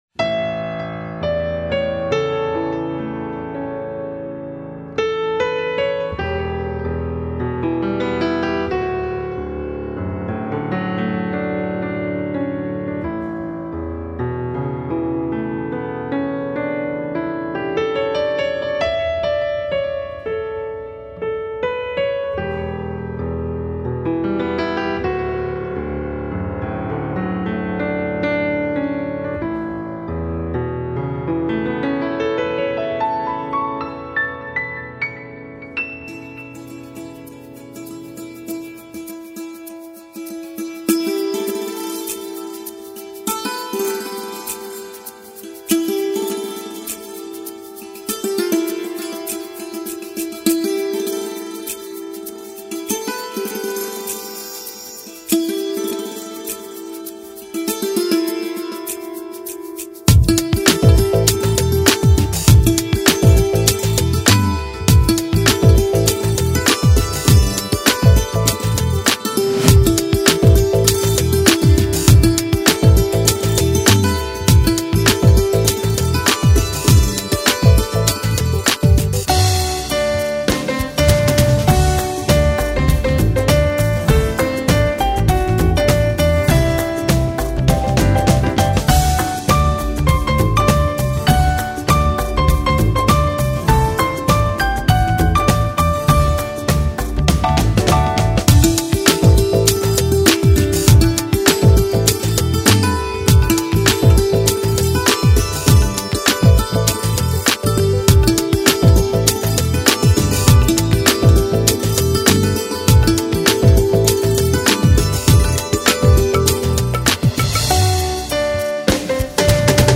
لایتموسیقی
[زبان موسیقی : لایت ( بدون کلام ) ]